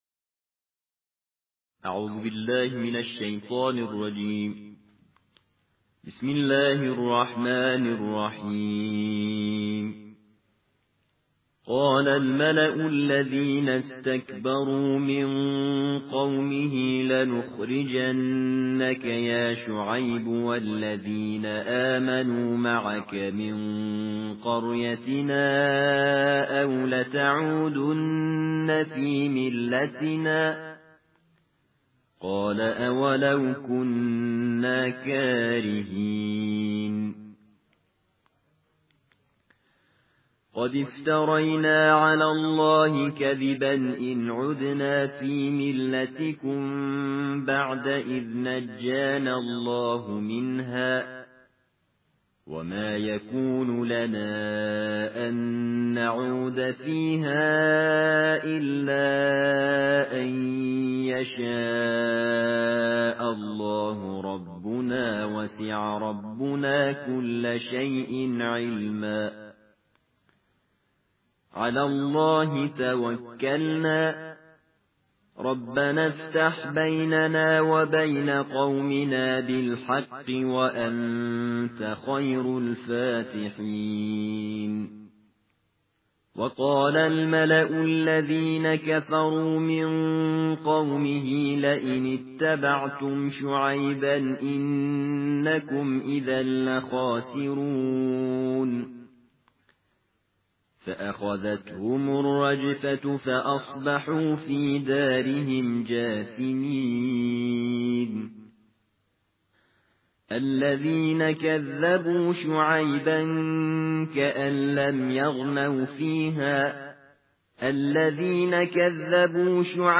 ترتیل جزء نهم قرآن